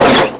collisions
car_light_1.wav